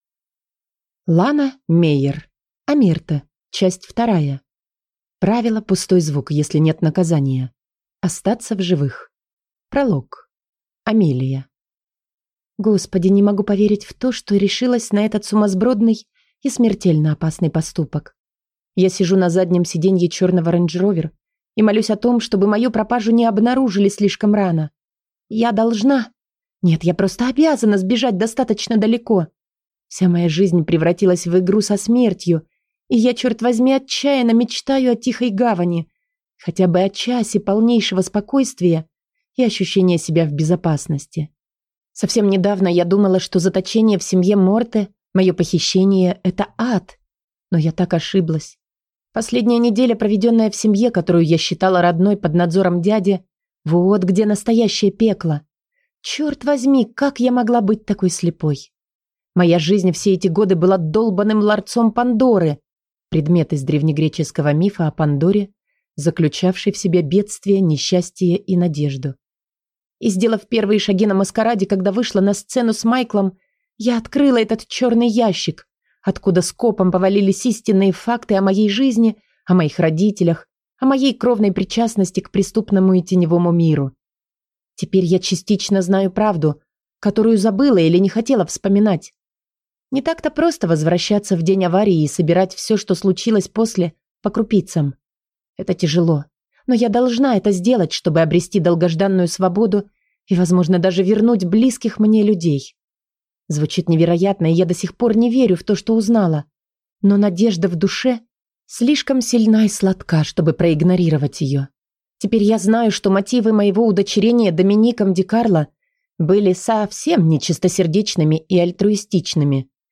Аудиокнига Омерта. Книга 2 | Библиотека аудиокниг